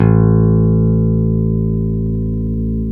Index of /90_sSampleCDs/Roland - Rhythm Section/BS _E.Bass 4/BS _Stretch Bass